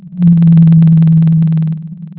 Blue whales mostly emit very loud, repetitive low-frequency rumbling sounds that can travel for many hundreds of kilometres underwater.
You can hear a short sound clip of a single blue whale call
bluewhale.wav